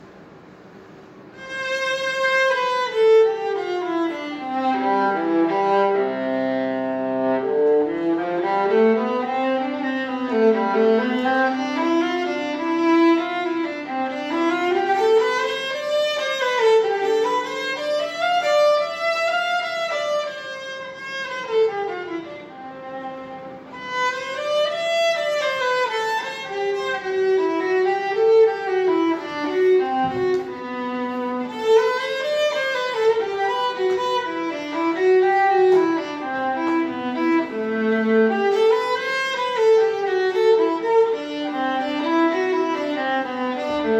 Performance, 2022.